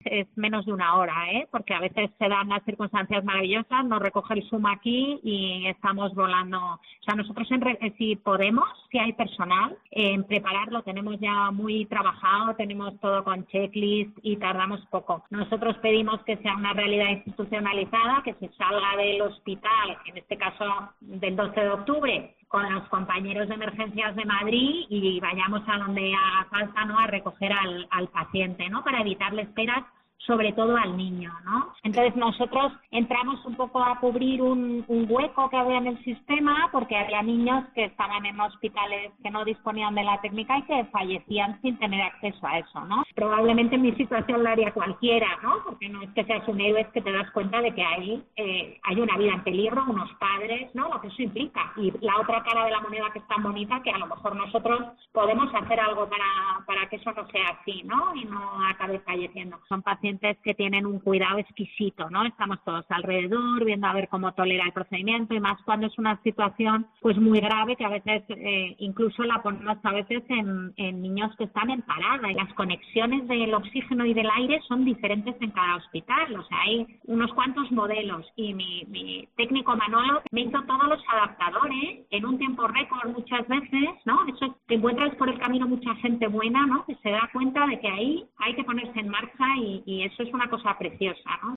En Cope hemos hablado con ella y nos ha explicado como funciona este dispositivo.